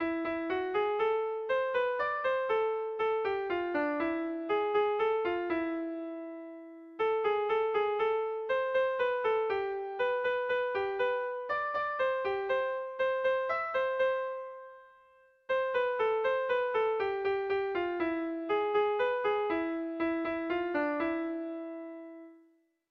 Melodías de bertsos - Ver ficha   Más información sobre esta sección
Tragikoa
Zazpi puntukoa, berdinaren moldekoa
ABDEF